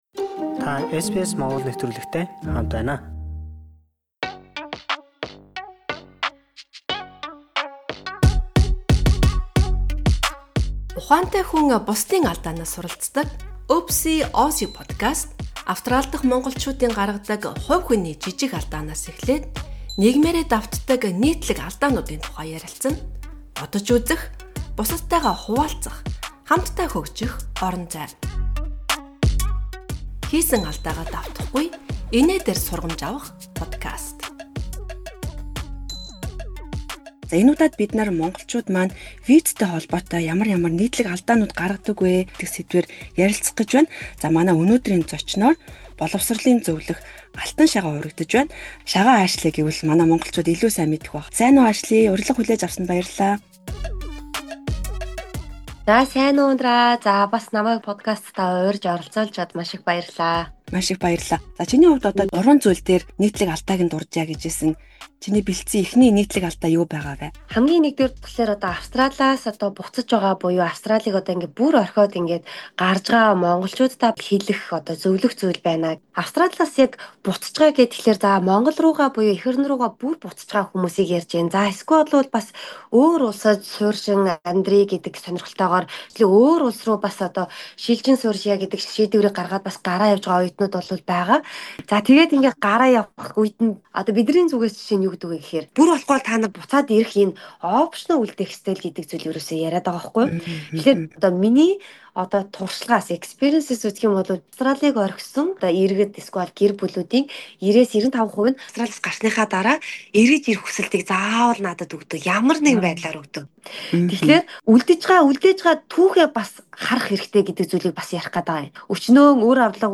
Боловсролын зөвлөх